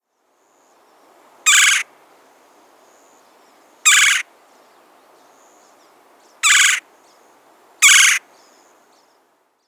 Red-bellied Woodpecker
How they sound: The Red-bellied Woodpecker’s most common call is a shrill, rolling kwirr or churr, but they are also well known for the sounds they make when they drum against wood or metal.
Red-bellied_Woodpecker_1_Kwirr_calls.mp3